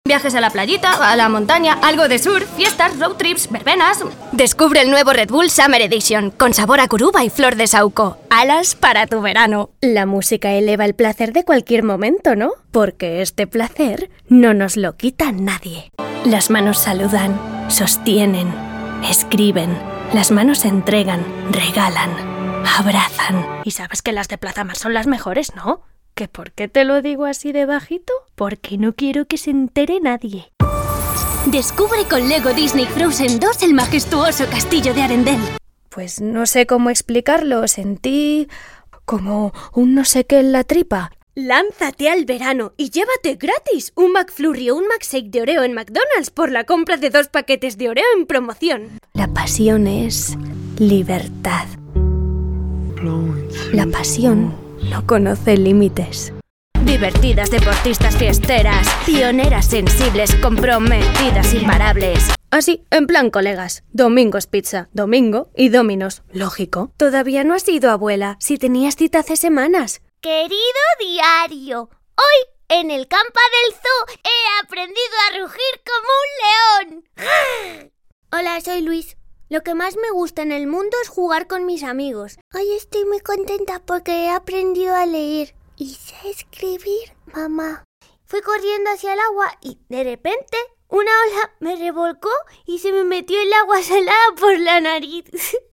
Demo doblaje diferentes registros